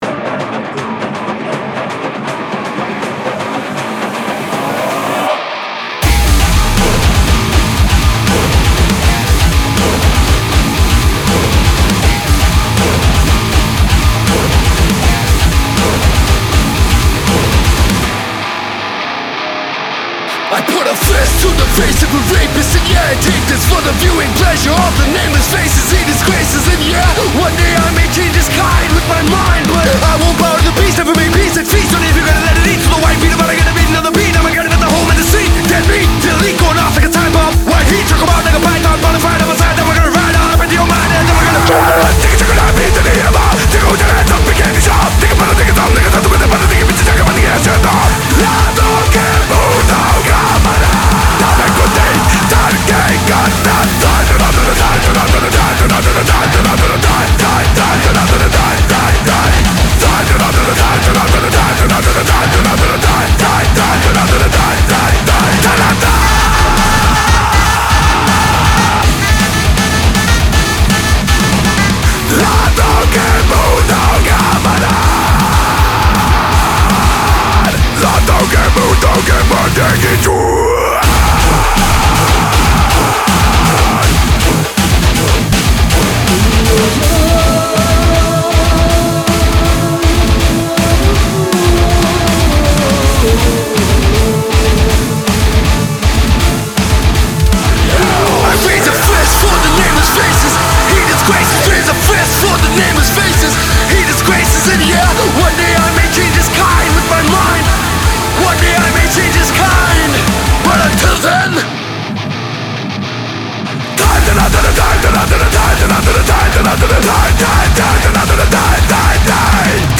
BPM160
Audio QualityCut From Video